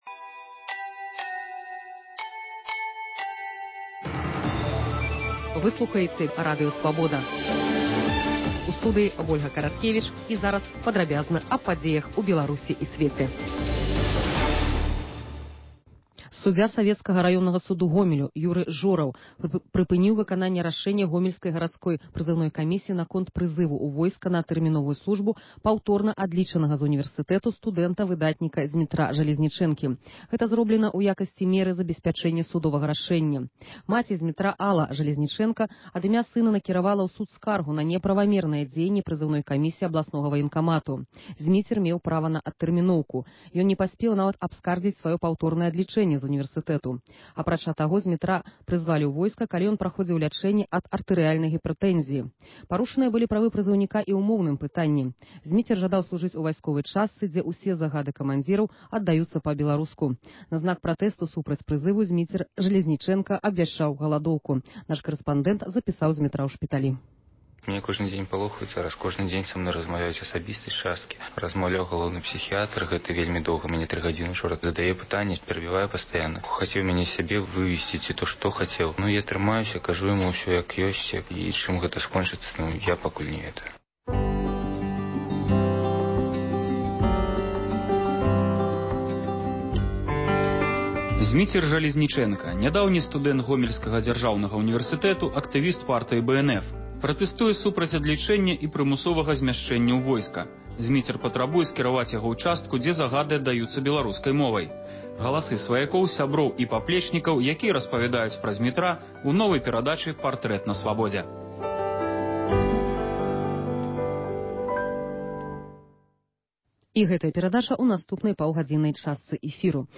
Паведамленьні нашых карэспандэнтаў, званкі слухачоў, апытаньні ў гарадах і мястэчках Беларусі.